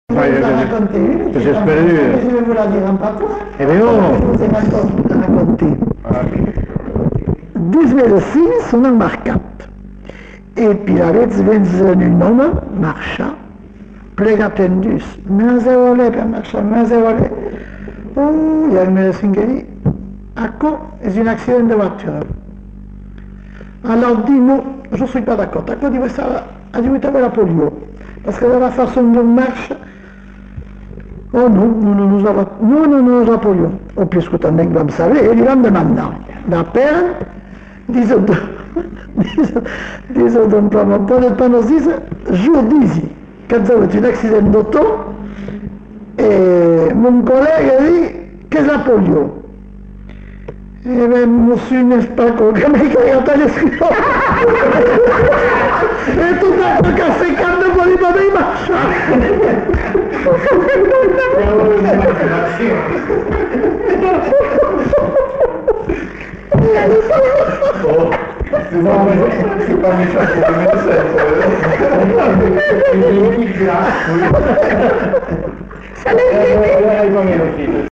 Aire culturelle : Bazadais
Lieu : Uzeste
Genre : conte-légende-récit
Type de voix : voix de femme
Production du son : parlé